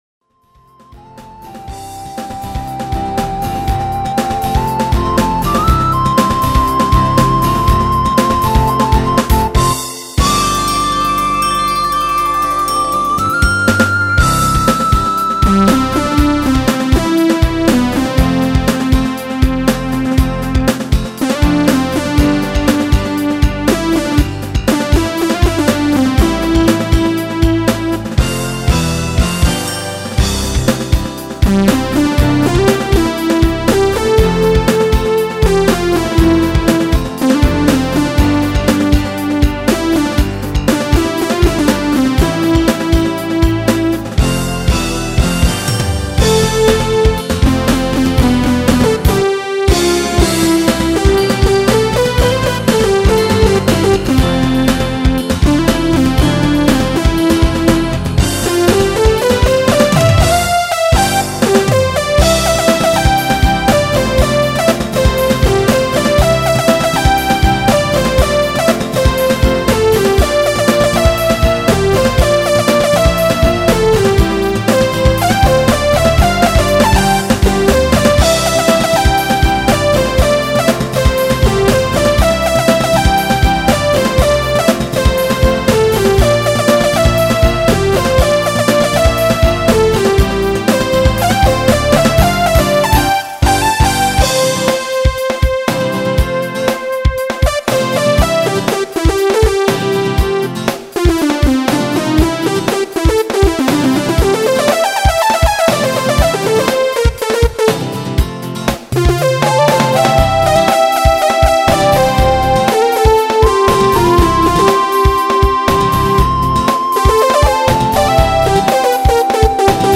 쉬운 코드와 쉬운 멜로디, 다른 말로하면 흔한 코드에 흔한 멜로디.. Sound Module : Roland Sound Canvas 88 Pro Software : Cakewalk 9.0, Sound Forge 7.0 너무 맑은 날 (prototype) 댓글 댓글 쓰기 목록 보기